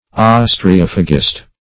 Search Result for " ostreophagist" : The Collaborative International Dictionary of English v.0.48: Ostreophagist \Os`tre*oph"a*gist\, n. [Gr.
ostreophagist.mp3